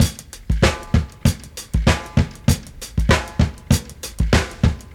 • 97 Bpm Drum Loop D# Key.wav
Free breakbeat - kick tuned to the D# note. Loudest frequency: 1158Hz
97-bpm-drum-loop-d-sharp-key-pUr.wav